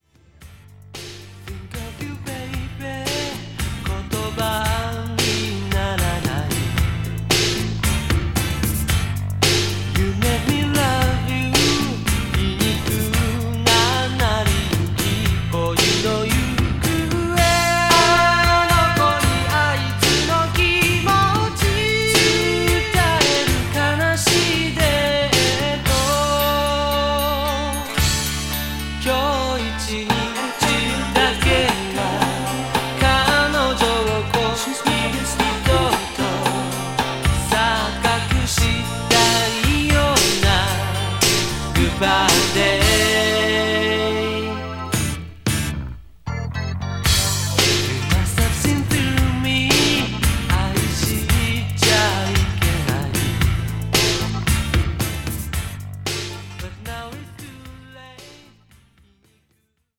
’60’~’70年代のポップ/ソウル・ヒットを'80年代のサウンド・センスでカバーした好盤です。